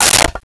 Plastic Plate 01
Plastic Plate 01.wav